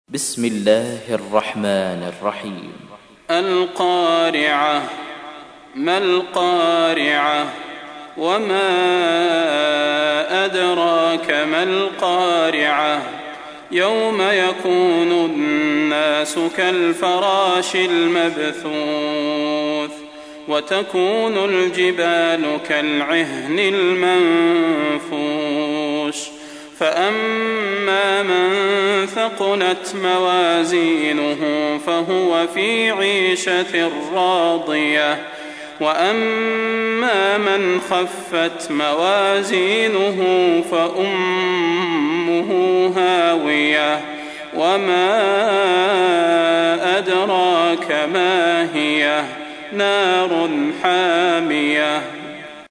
تحميل : 101. سورة القارعة / القارئ صلاح البدير / القرآن الكريم / موقع يا حسين